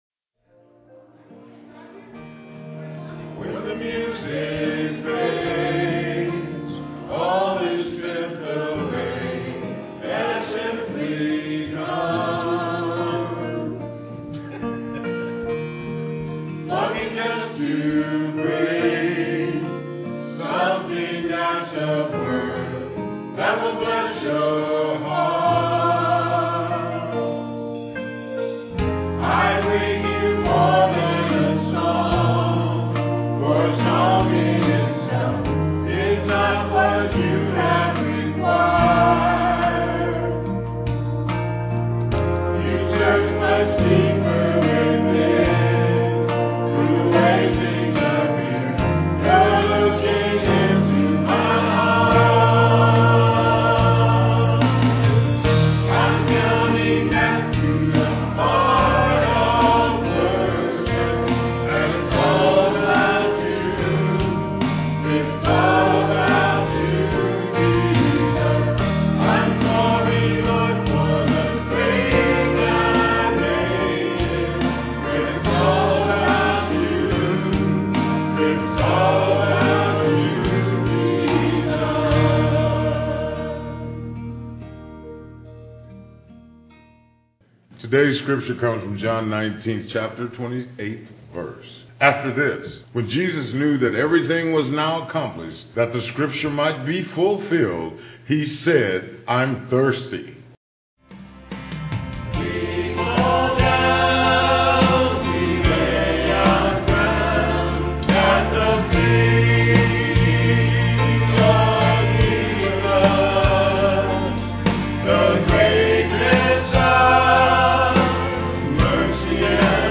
Piano offertory